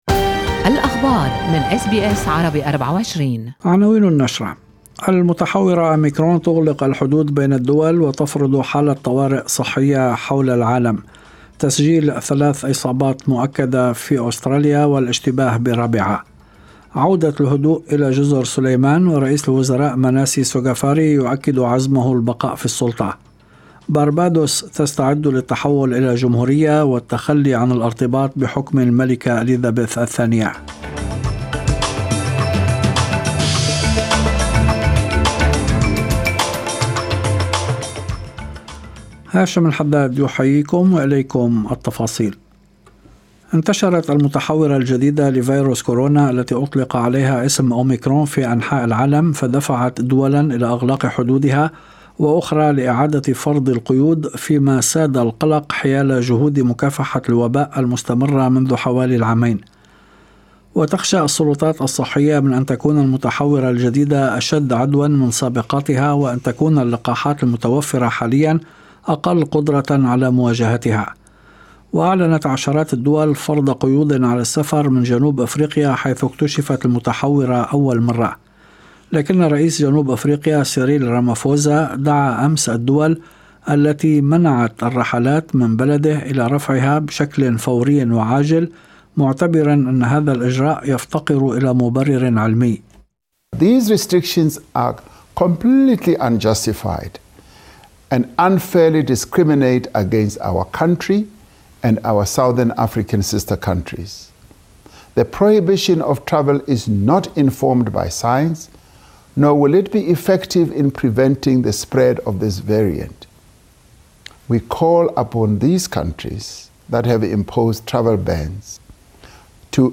نشرة أخبار المساء 29/11/2021